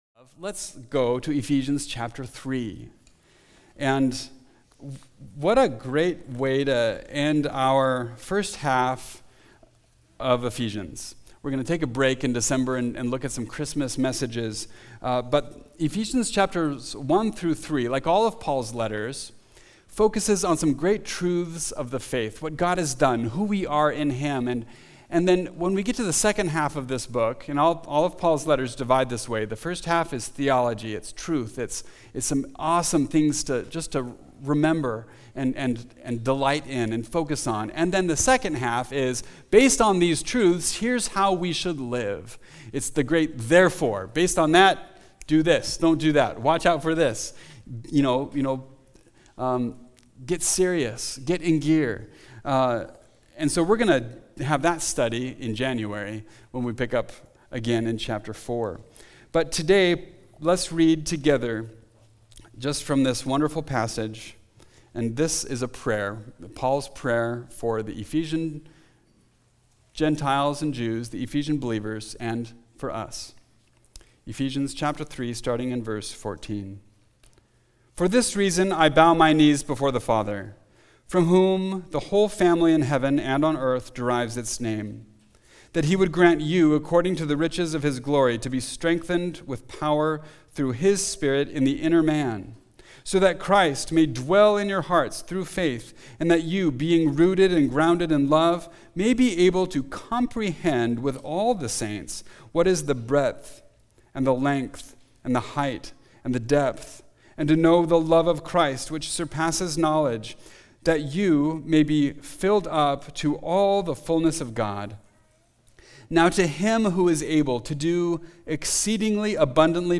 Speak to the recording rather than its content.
Together for Thanksgiving – Mountain View Baptist Church